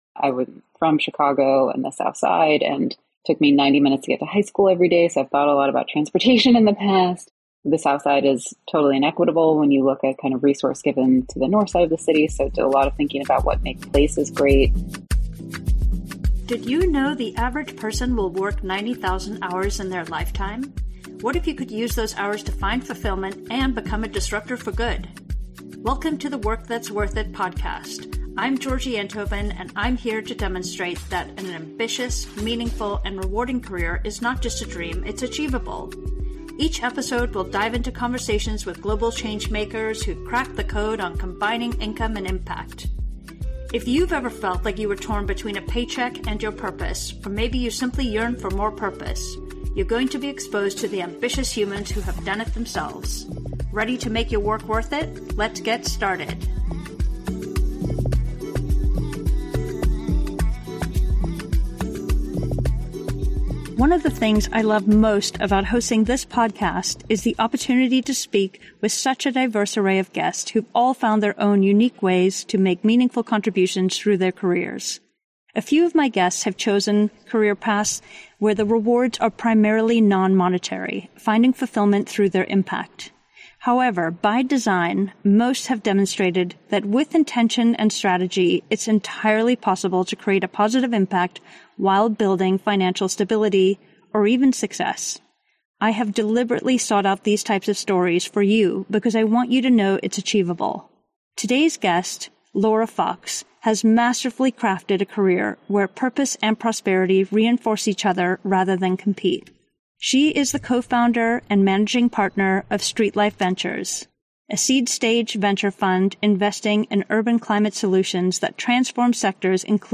In this captivating conversation